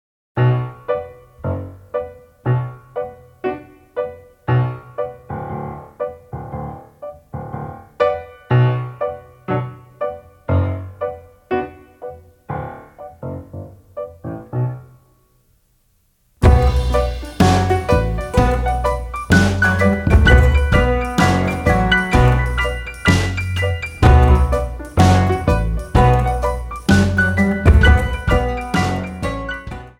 takes the African guitar sound
Kora, Kamelngoni, guitar, percussion
drums, congas
guitar, piano, fiddle
bass, guitar
Djembe, percussion
Funk
Instrumental
New Age
Rock
World Rhythms